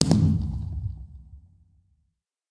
largeblast_far1.ogg